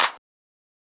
Clap (One).wav